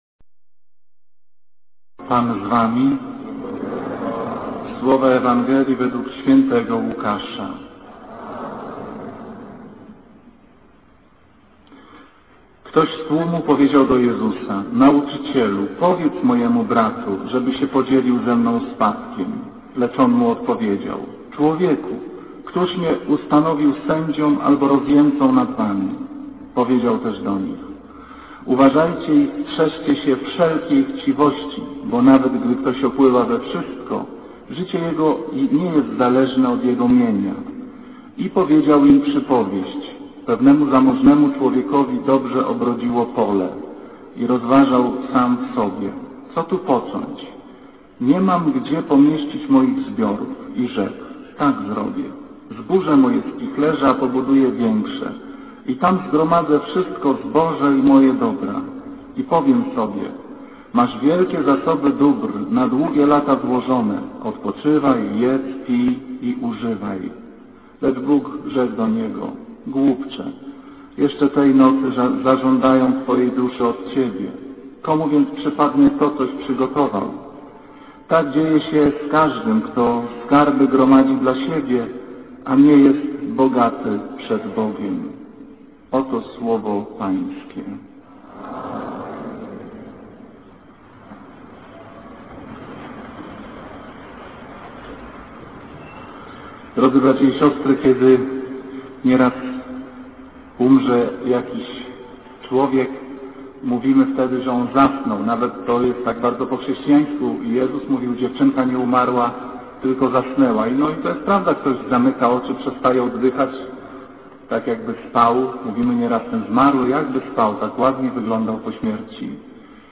Kazanie z 5 sierpnia 2007r.
niedziela, godzina 15:00, kościół św. Anny w Warszawie « Kazanie z 29 lipca 2007r.